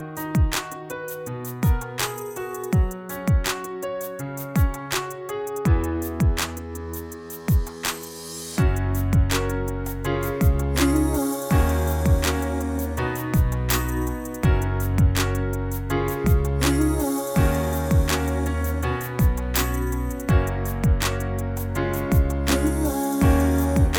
no Backing Vocals Soundtracks 3:35 Buy £1.50